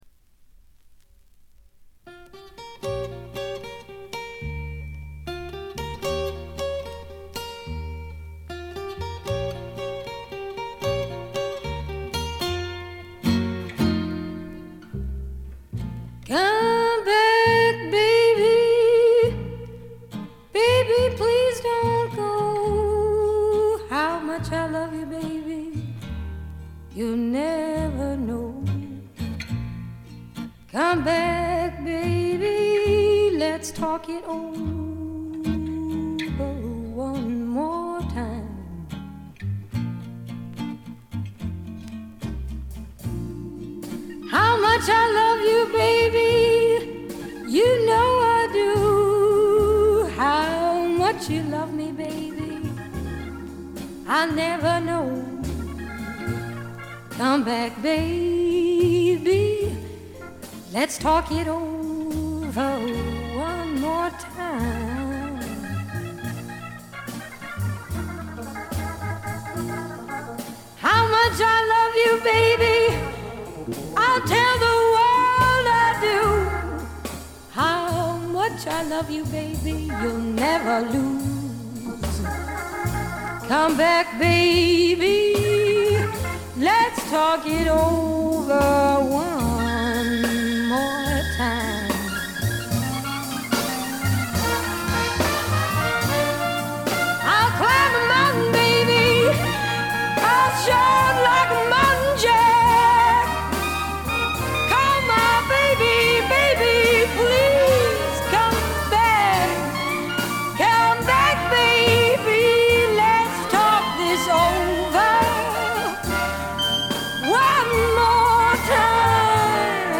微細なノイズ感のみ。
ここでの彼女はみずからギターを弾きながら歌う渋い女性ブルースシンガーという側面を見せてくれます。
激渋アコースティック・ブルースにオールドジャズやR&Bのアレンジが施されたサウンド作りもいい感じですね。
試聴曲は現品からの取り込み音源です。